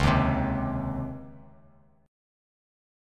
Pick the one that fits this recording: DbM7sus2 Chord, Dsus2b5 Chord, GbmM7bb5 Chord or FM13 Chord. DbM7sus2 Chord